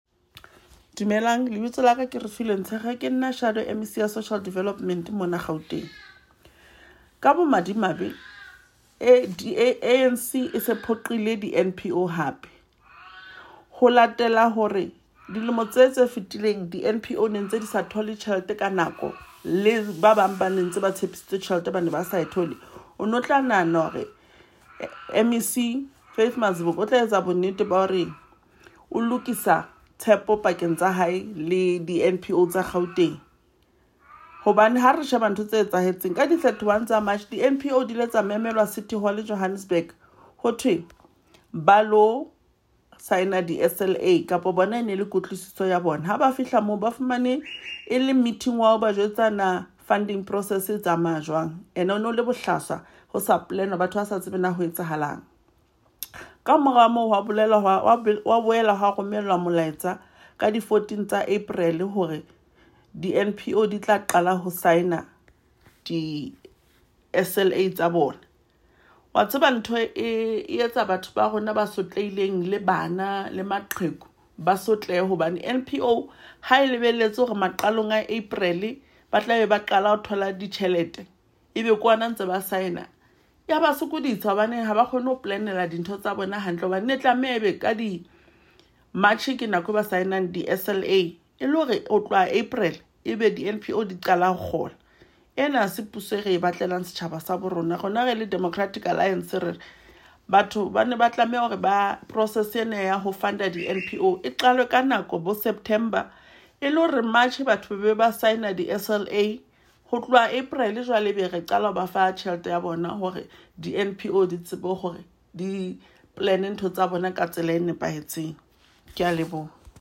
Sesotho soundbite by Refiloe Nt’sekhe MPL.